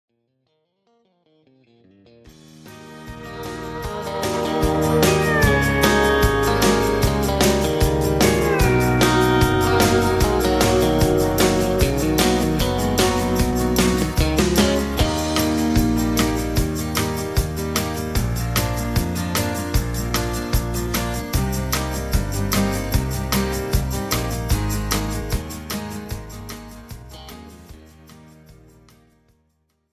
This is an instrumental backing track cover.
• Key – E♭
• With Backing Vocals
• No Fade